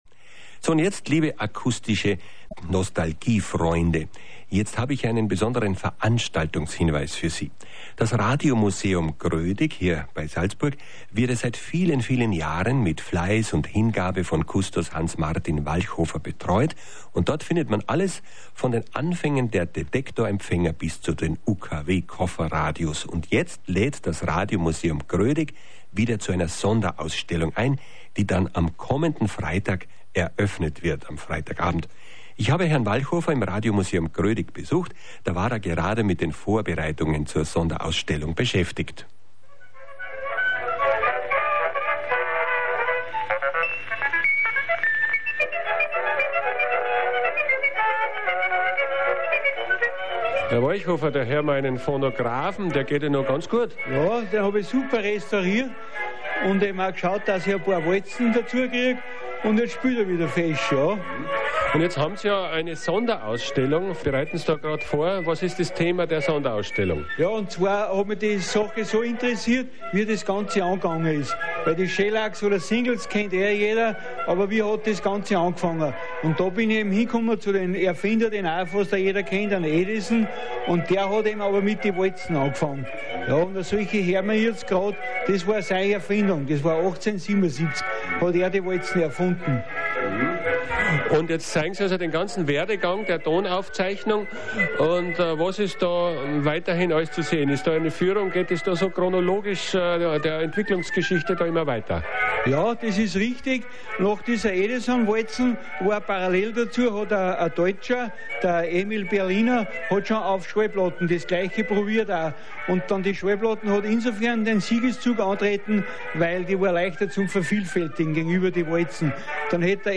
In der 362. Plattenkiste war folgendes Interview